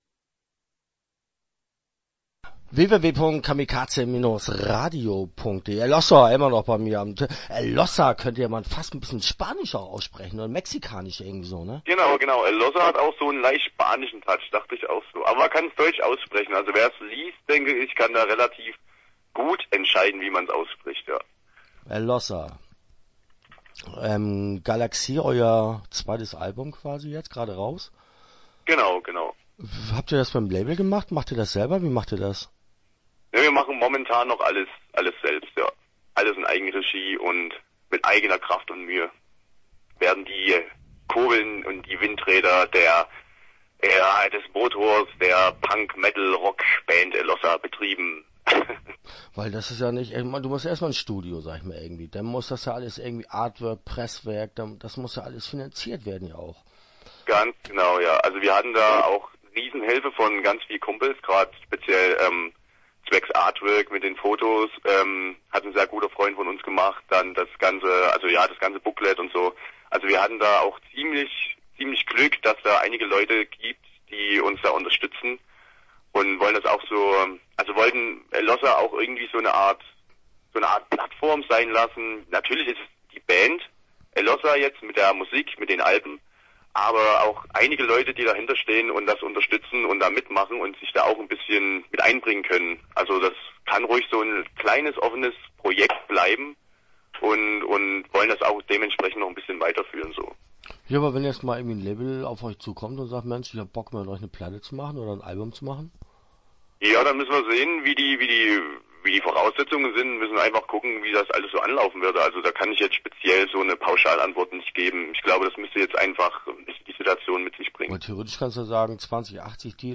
Elossa - Interview Teil 1 (10:06)